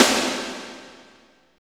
49.02 SNR.wav